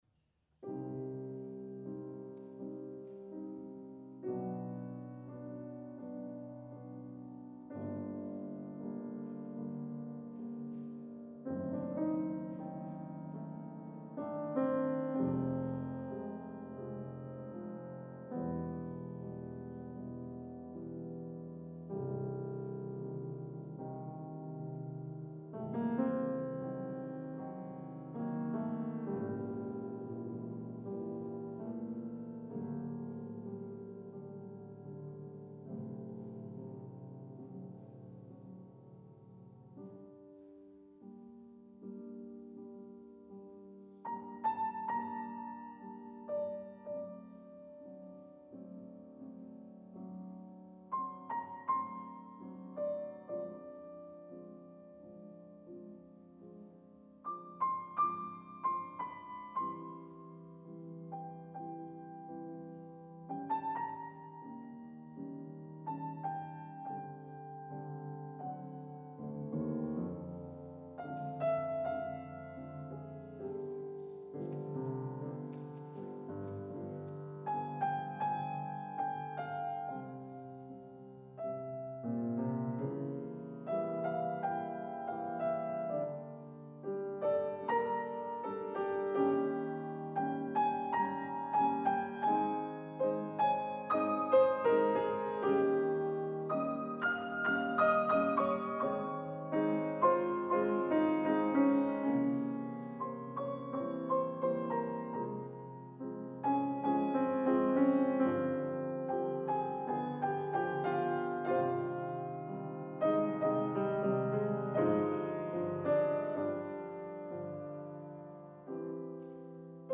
фотепиано